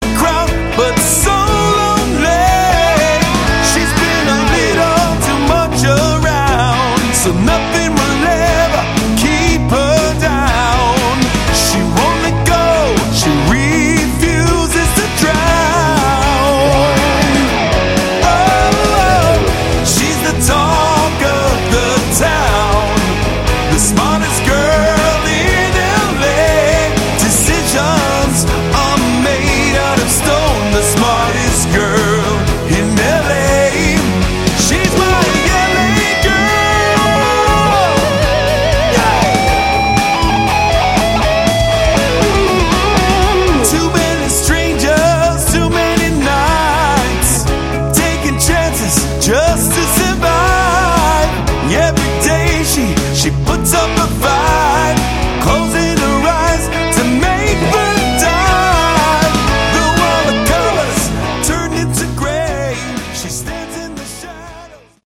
Melodic Rock